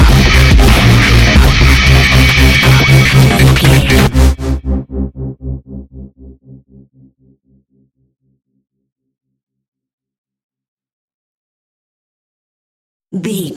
Epic / Action
Fast paced
Aeolian/Minor
aggressive
powerful
dark
intense
synthesiser
drum machine
breakbeat
energetic
synth leads
synth bass